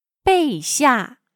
备下/Bèixià/Preparar, arreglar algo que se va a ofrecer.